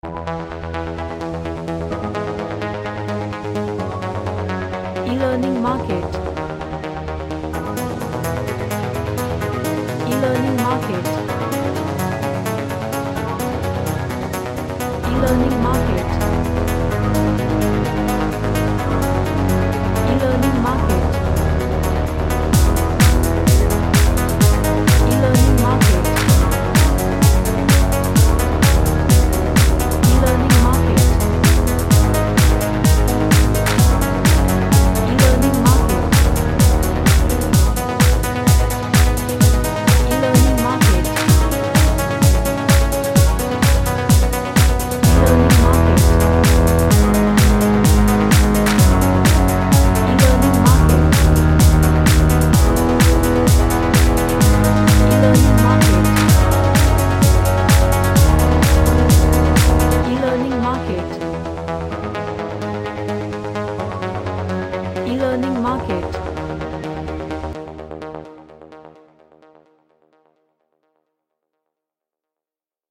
A electronic track with arp.
Energetic